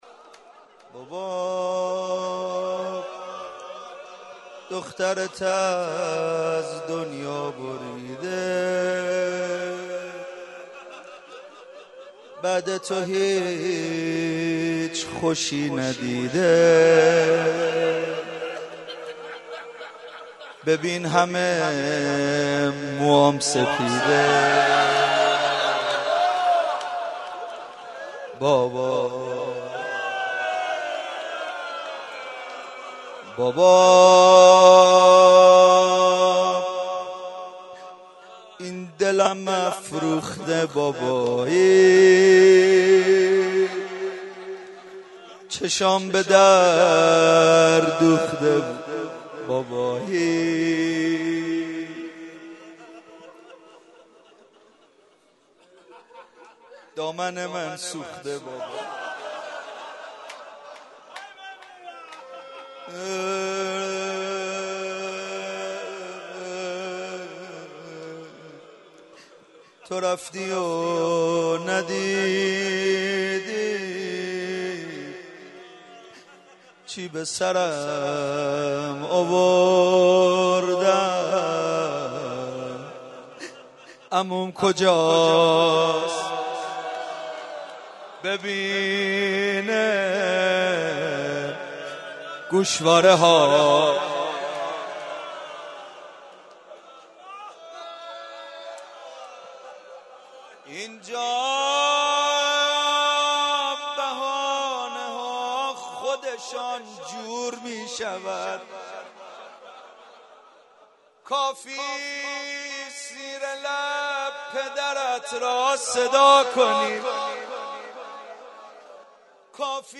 04.rozeh.mp3